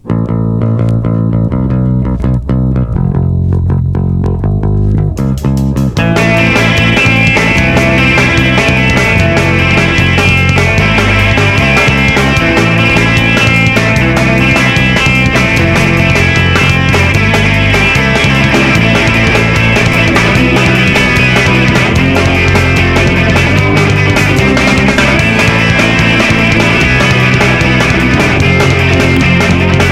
Rock oi